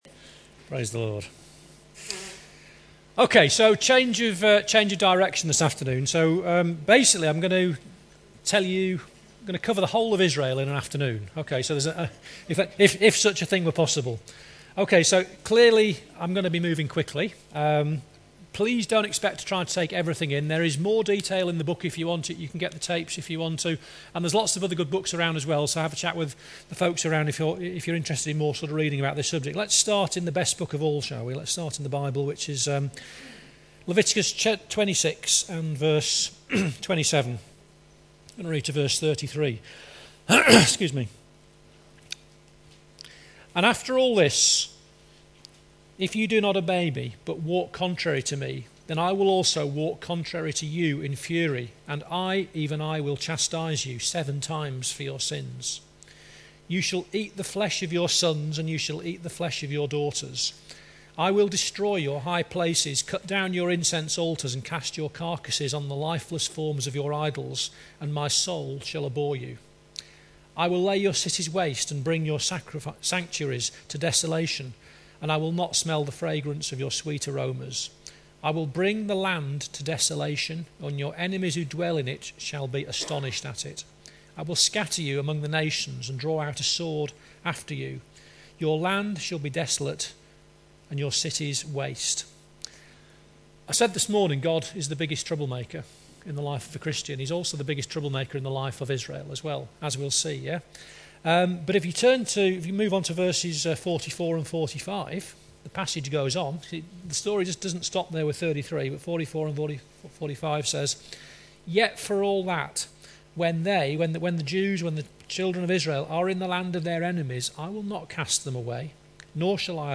You can listen to Module 4 Part #1 here, recorded at the UK International Embassy Jerusalem Prophetic Conference, Wigan 2015.